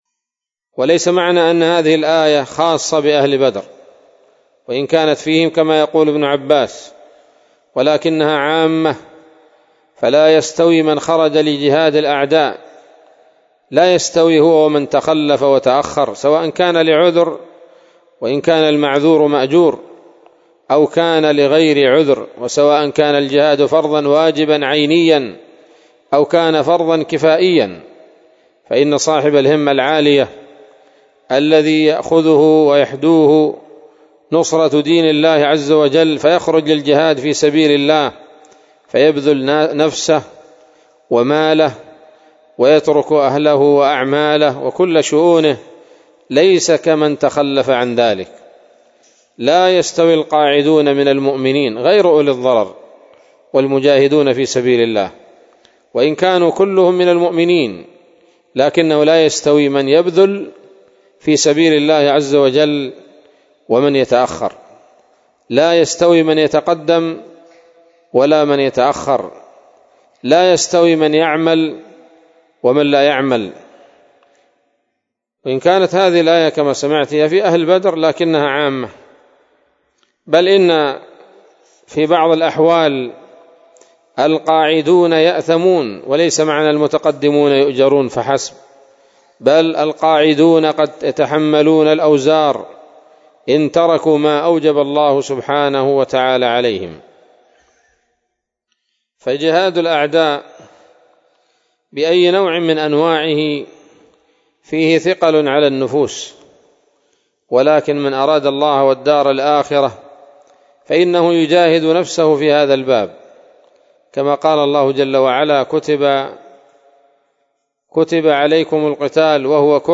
من درس صحيح البخاري – من كتاب المغازي – عند حديث: